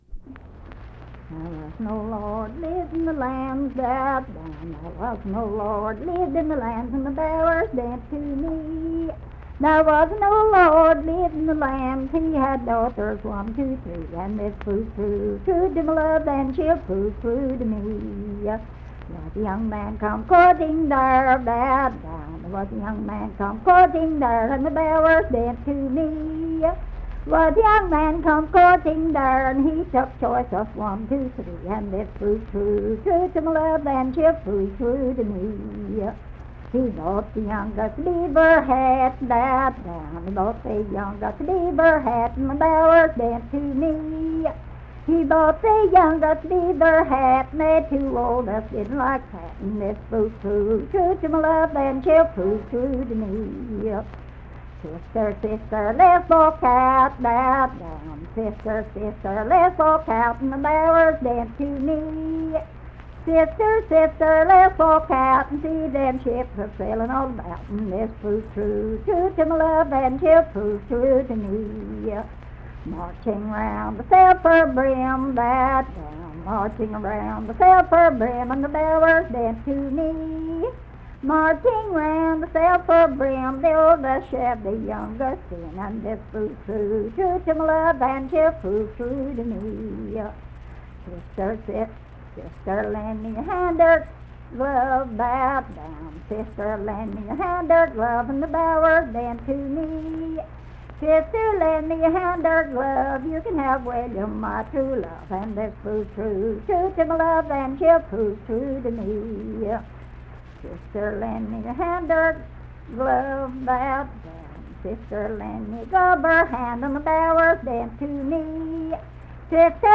Unaccompanied vocal music
Verse-refrain, 10(6w/R).
Voice (sung)
Logan County (W. Va.)